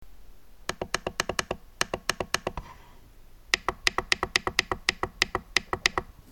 Pastarieji jau skleidžia daug triukšmo.